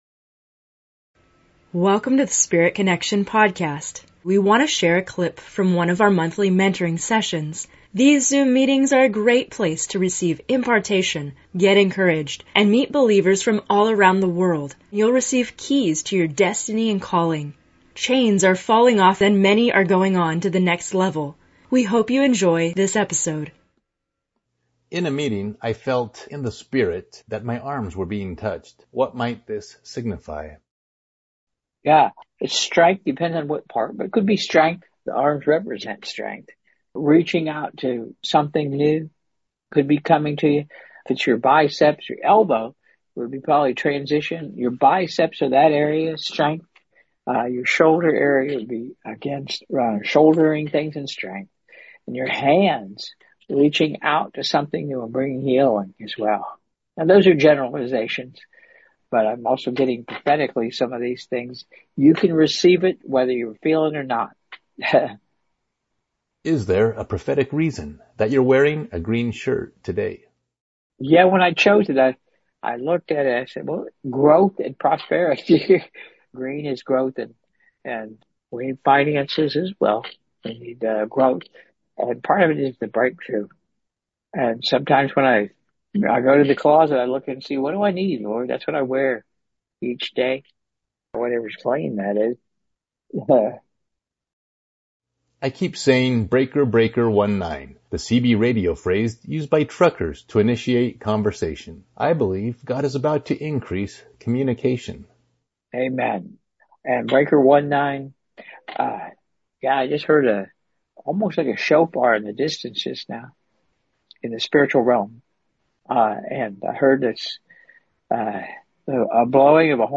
In this episode of Spirit Connection, we have a special excerpt from the latest Monthly Mentoring Session Q&A.